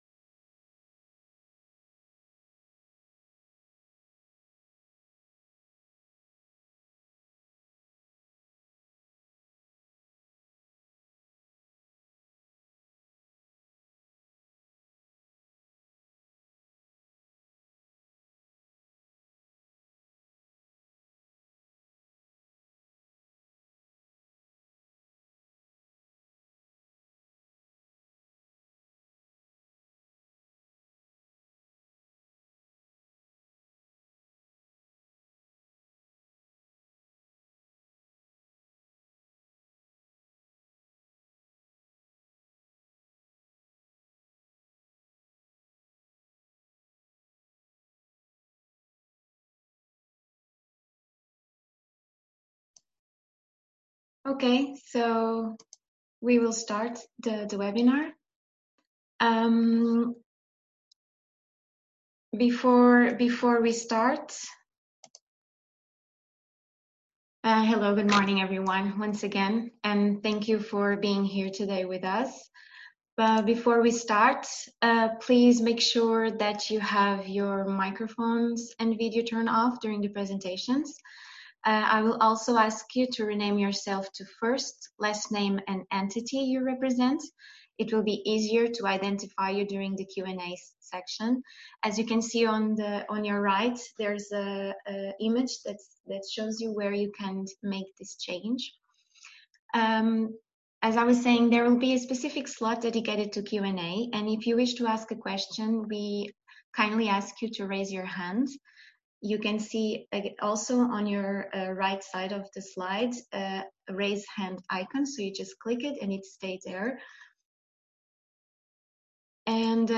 DigiCirc 1st Q&A Webinar on the Circular Cities open call, online